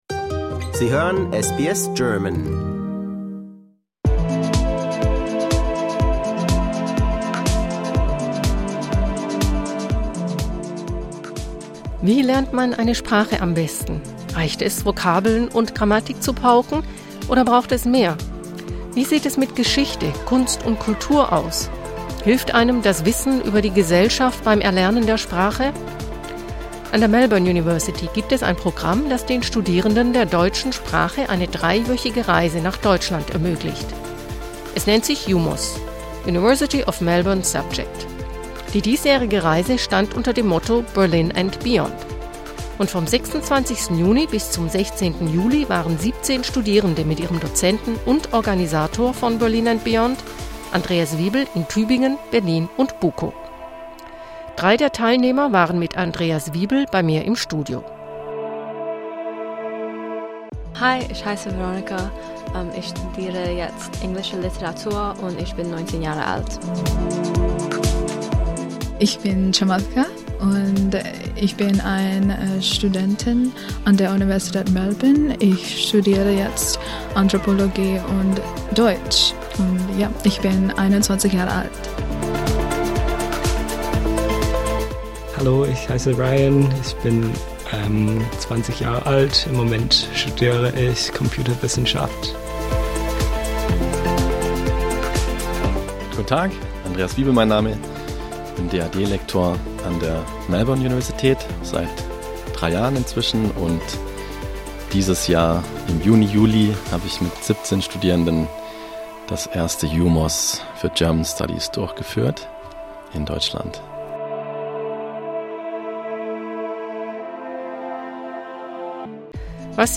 Im Melbourner Studio waren zu Gast